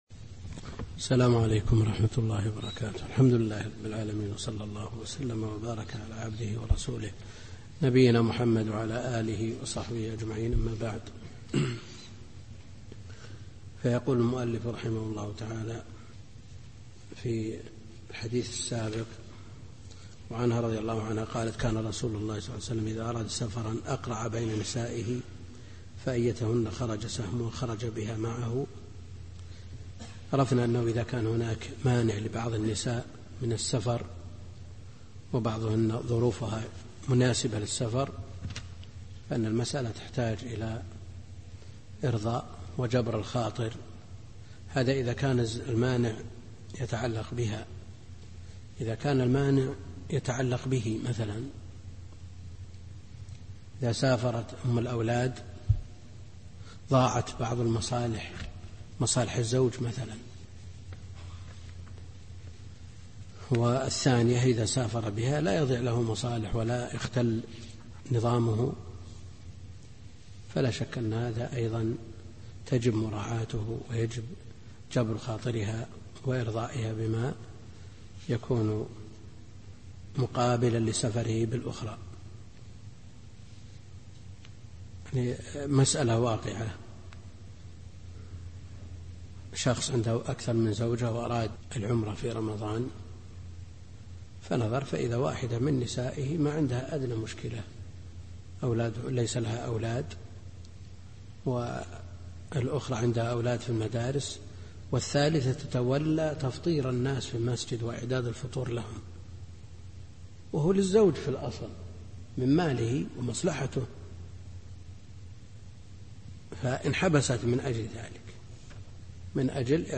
الدرس الرابع عشر من دروس شرح بلوغ المرام كتاب النكاح للشيخ عبد الكريم الخضير